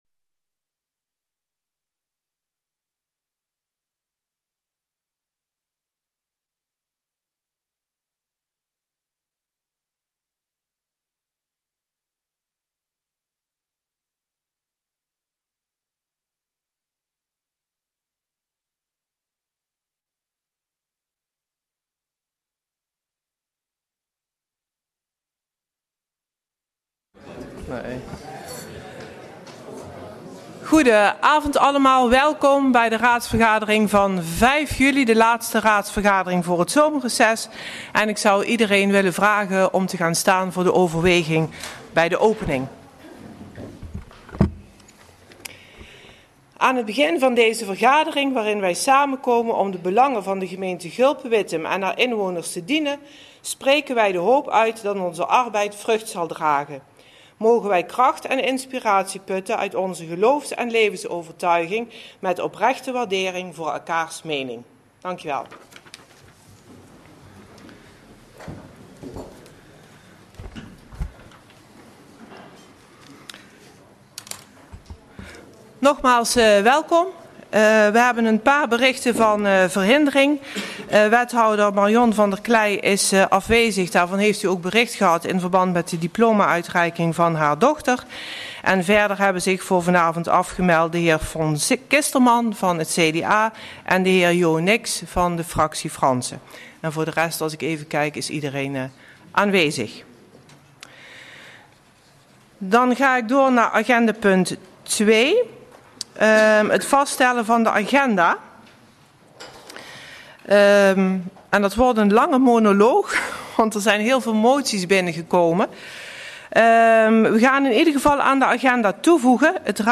Locatie Raadzaal